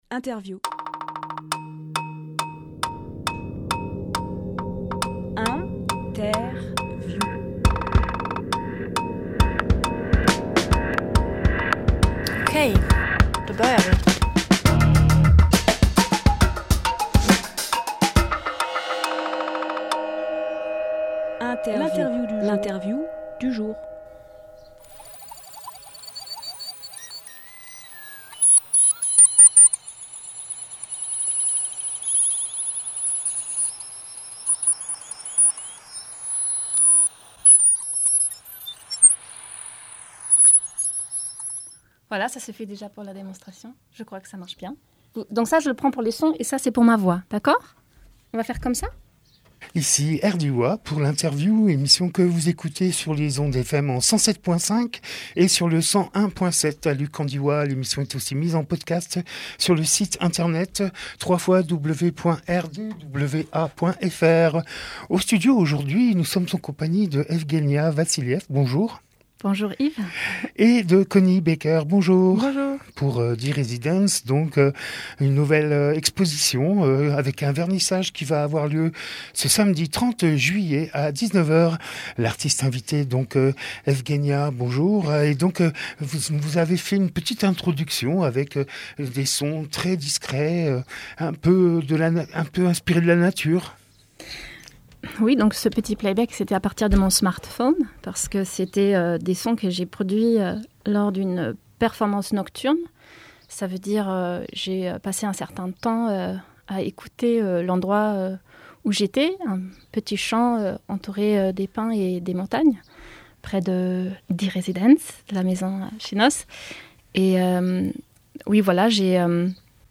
Emission - Interview
25.07.22 Lieu : Studio RDWA Durée